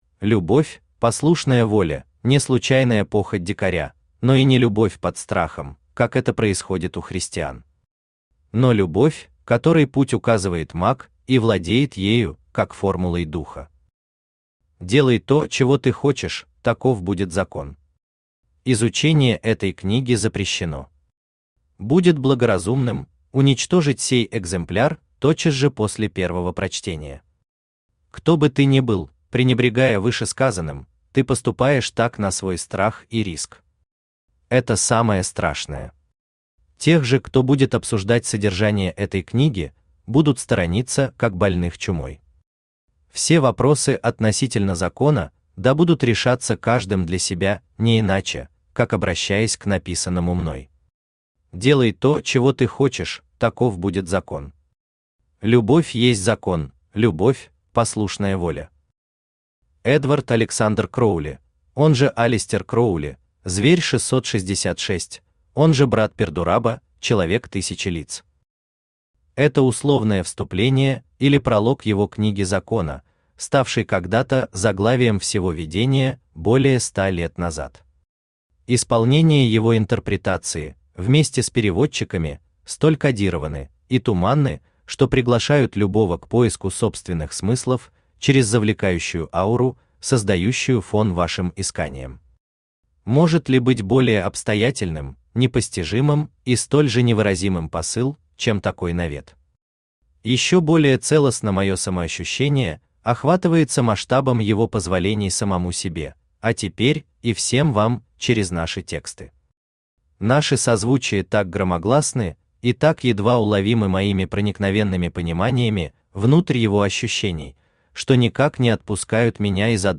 Aудиокнига Понимания Делания Автор Александр Алексеевич Ананьев Читает аудиокнигу Авточтец ЛитРес.